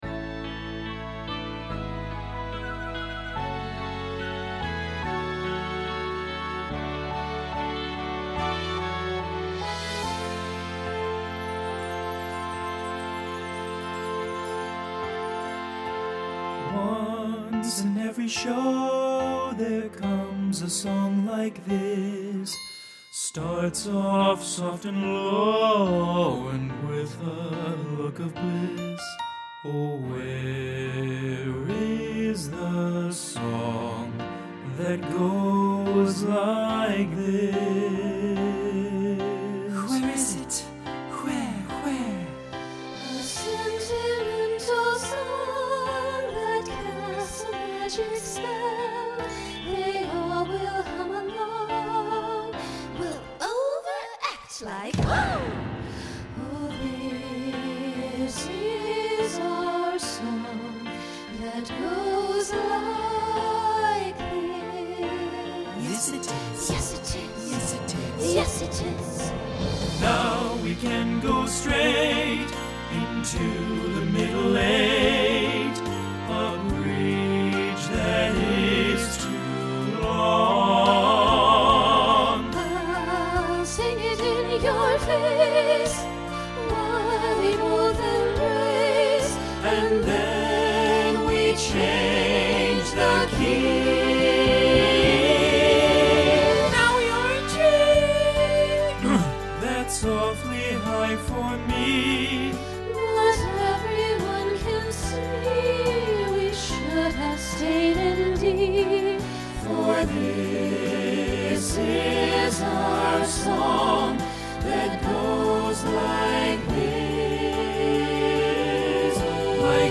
Voicing SATB Instrumental combo Genre Broadway/Film
Ballad , Novelty